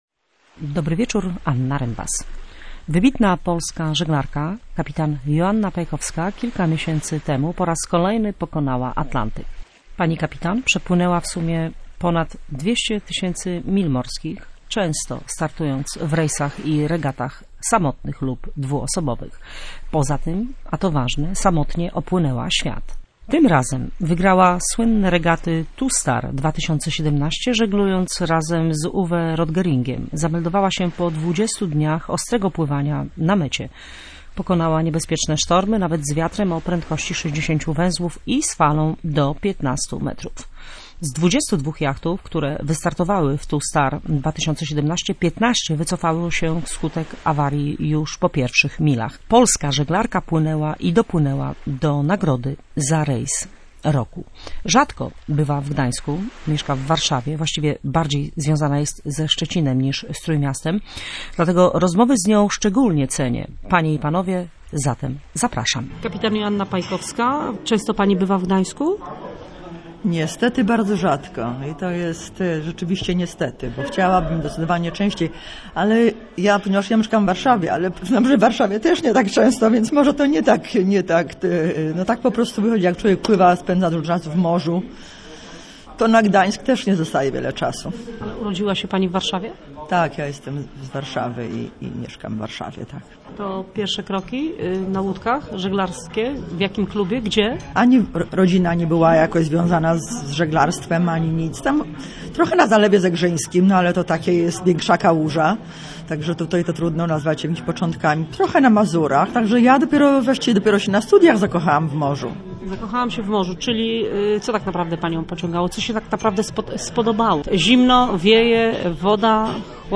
Zanurzymy się w Bałtyku z gdańskimi morsami podczas noworocznej kąpieli